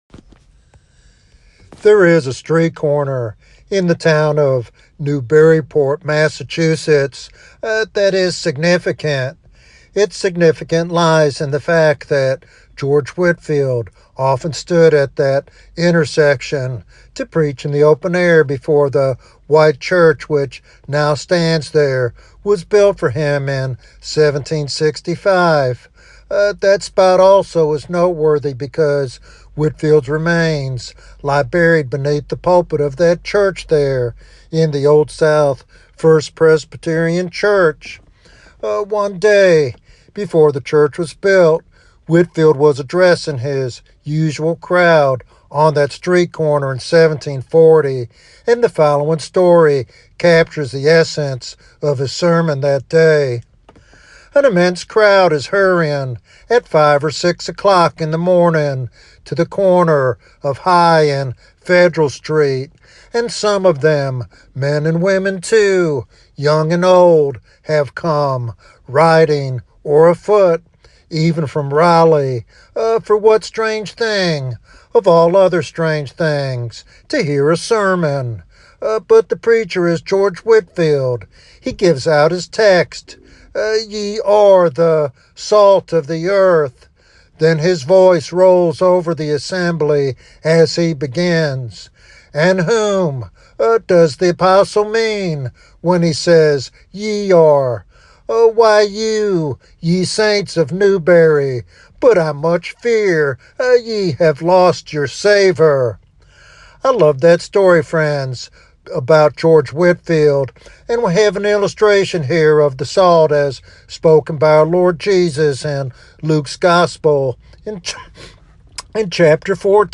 This sermon encourages believers to live lives that make Christ preeminent and impactful in the world.